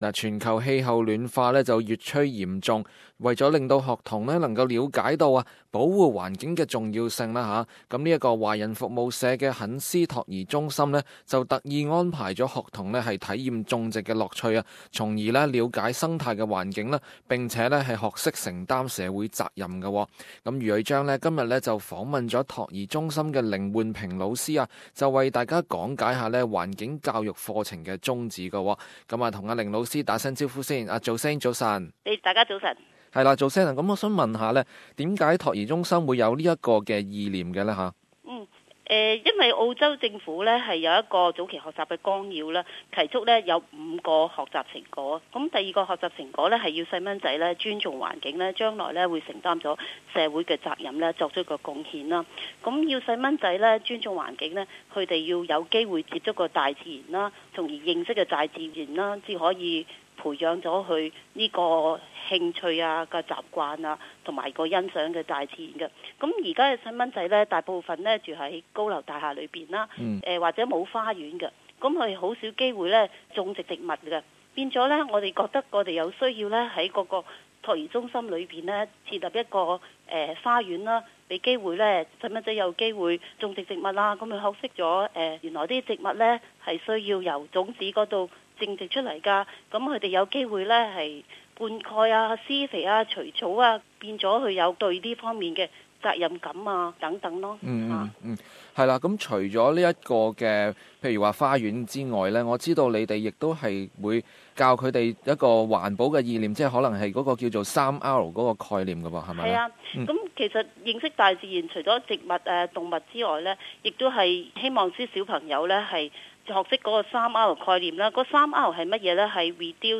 【社區專訪】托兒中心培養學童尊重環境